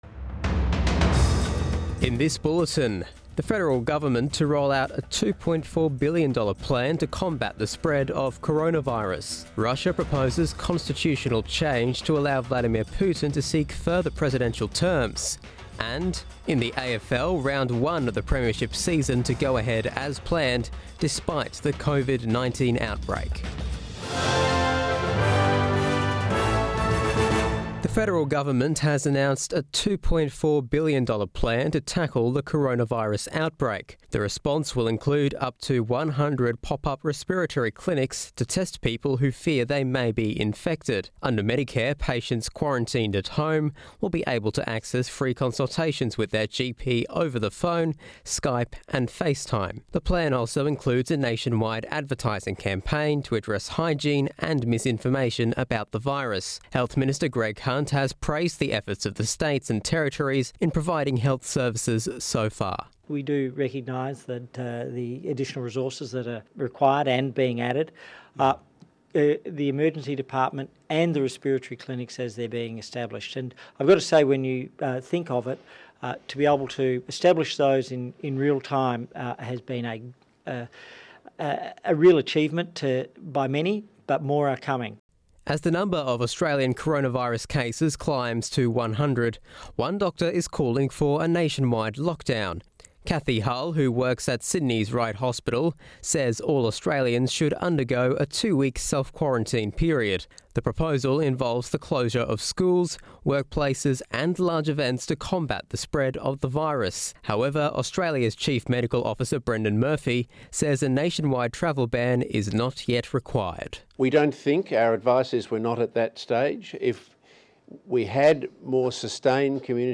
AM Bulletin March 11 2020